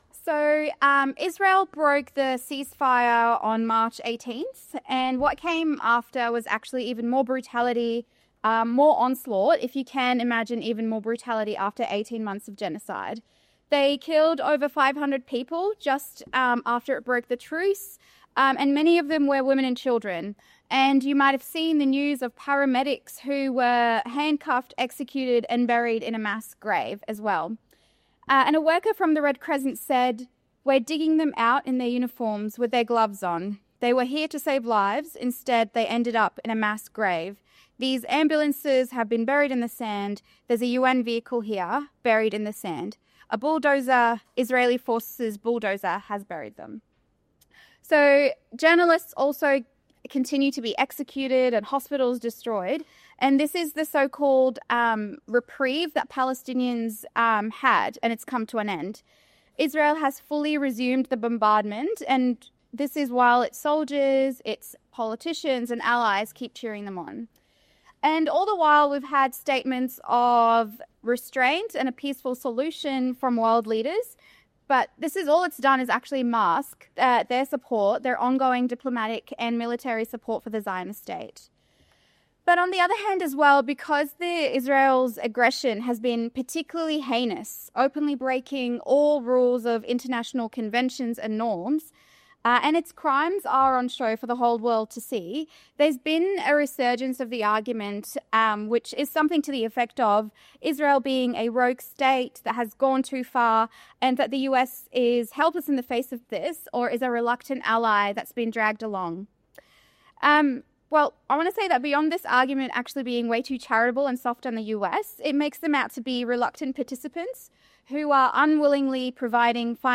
Marxism 2025 Play talk Download Marx Talks is a project of Socialist Alternative